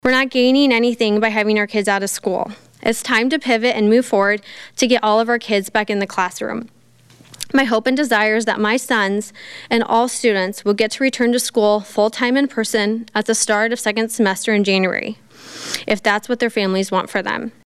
During a news conference late this (Wednesday) morning, Reynolds invited a parent to speak about her frustrations with Ankeny schools.